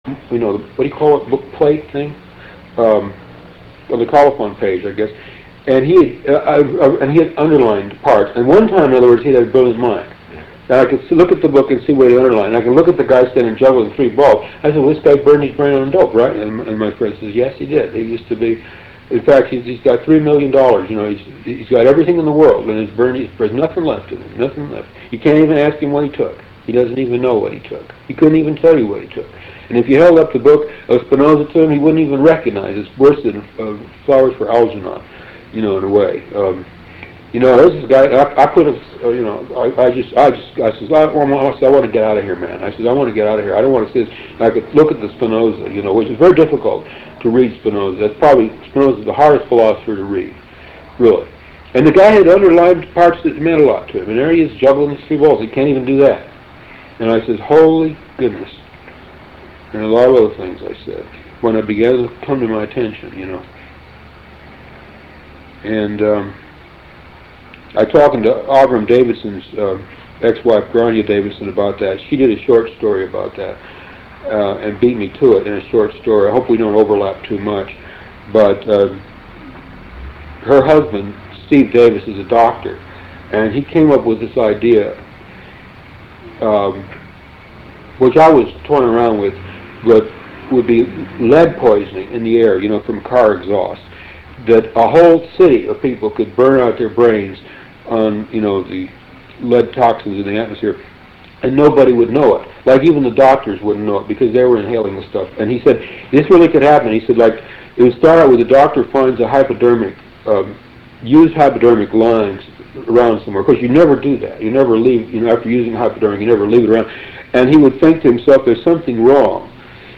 Interview with Philip K Dick 8